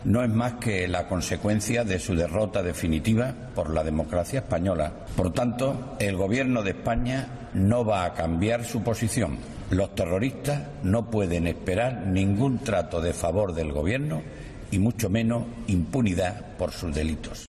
En una declaración institucional tras la confirmación del desarme de ETA, Zoido ha asegurado que la banda terrorista "está operativamente derrotada, sin futuro y con sus dirigentes en prisión".